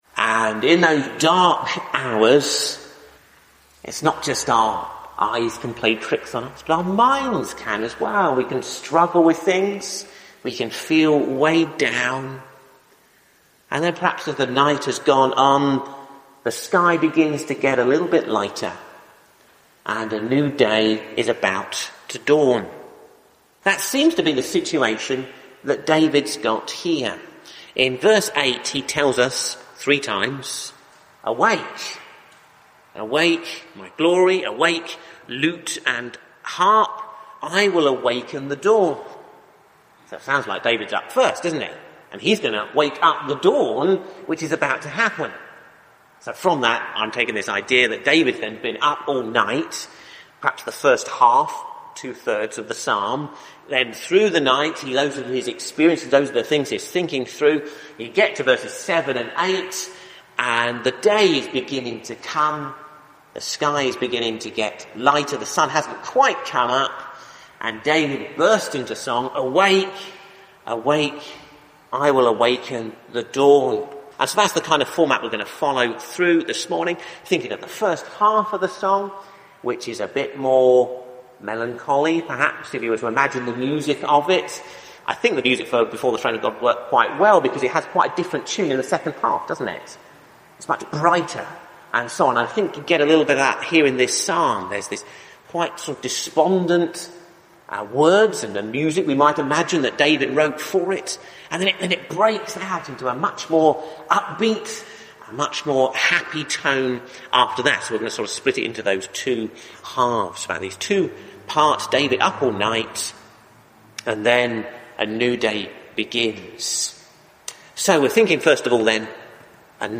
Sermons: Ashbourne Baptist Church 2025 | (Hope For Ashbourne YouTube channel)